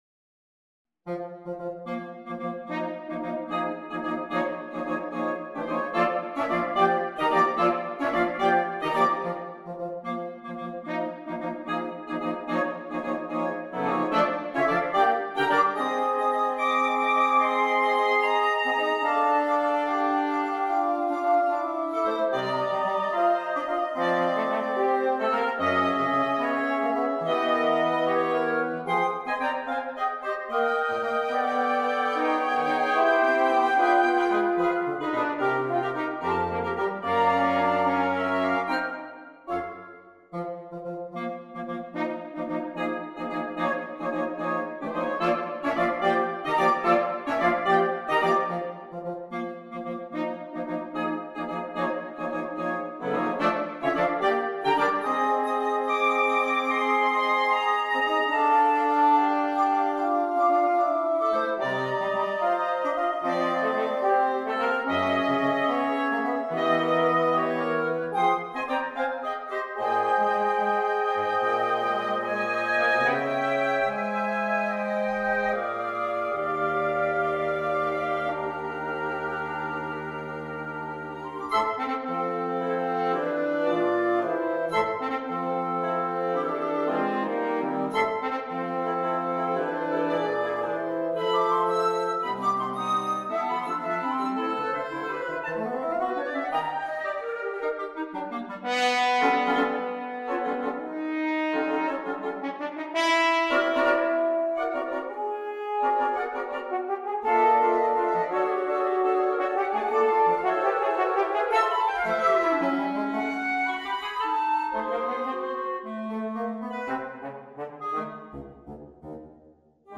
PER QUINTETTO DI FIATI